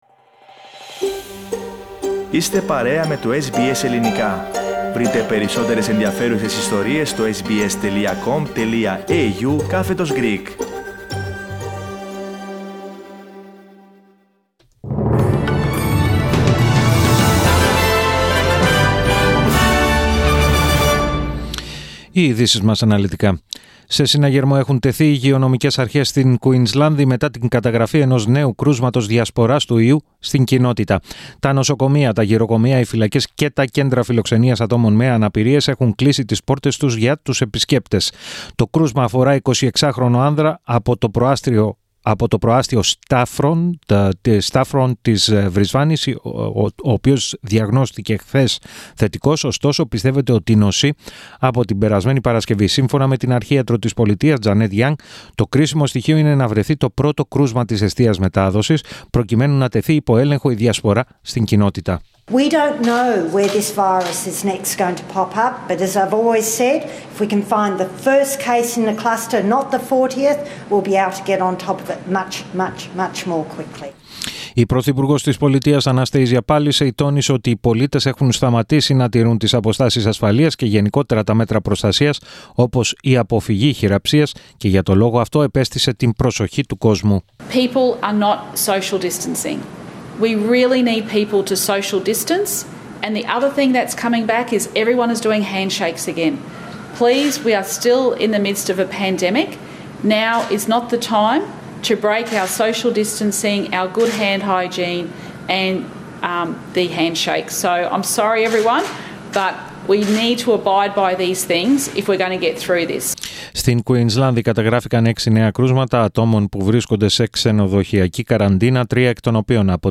Ειδήσεις 26.03.21